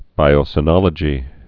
(bīō-sə-nŏlə-jē)